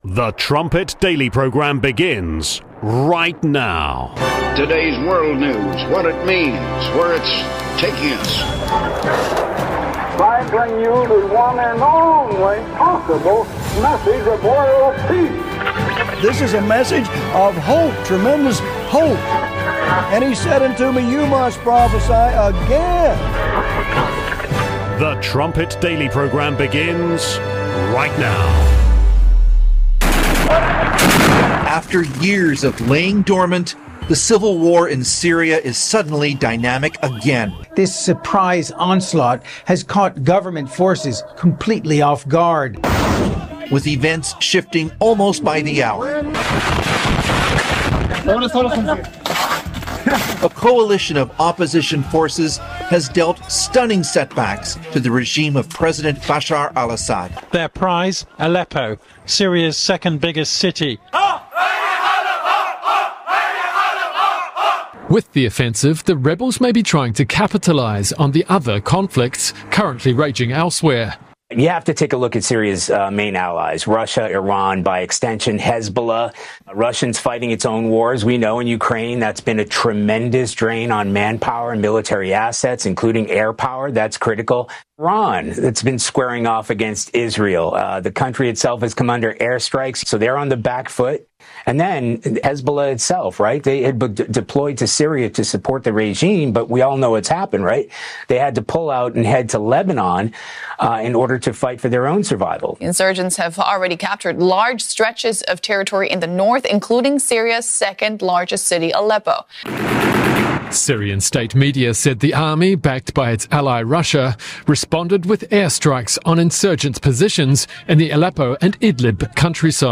Trumpet Daily Radio Show brings you a deeper understanding of the Bible and how it connects to your world and your life right now.